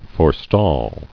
[fore·stall]